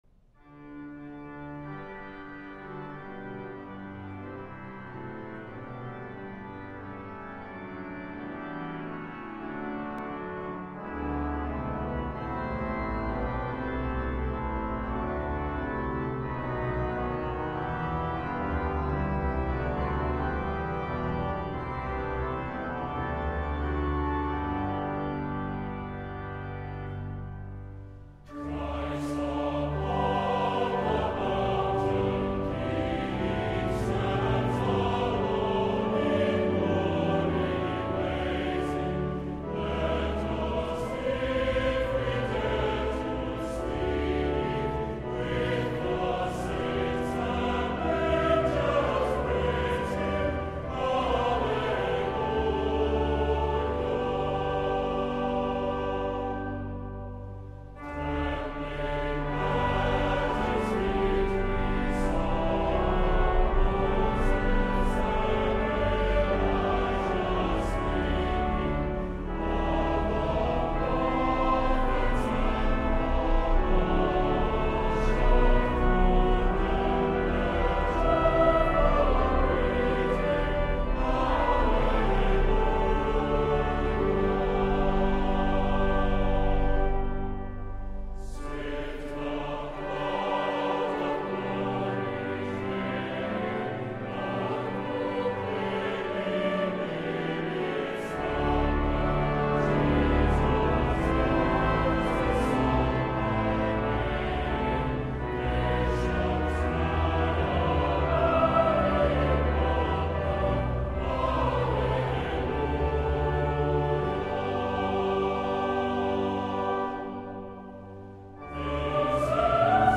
Online Worship and Music Bulletin
CLOSING HYMN Christ Upon the Mountain Peak           Music: Cyril Vincent Taylor (1962)